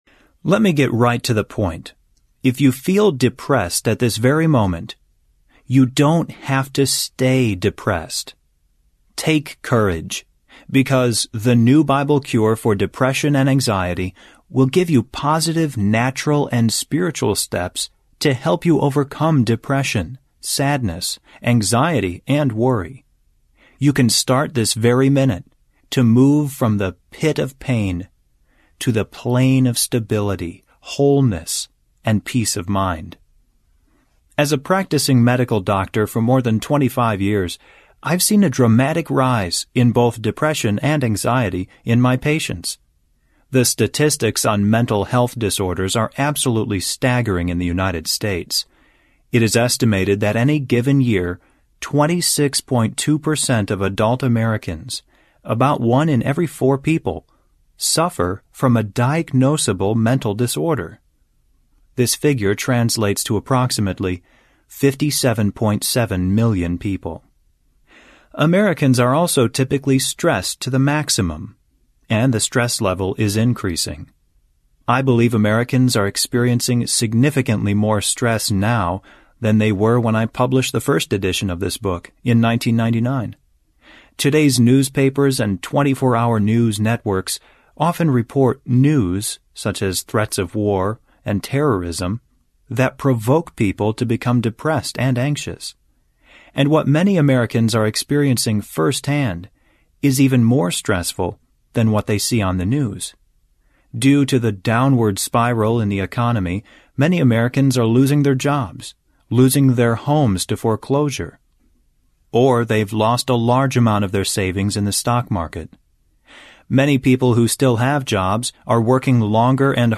The New Bible Cure for Depression and Anxiety (Bible Cure) Audiobook
2.9 Hrs. – Unabridged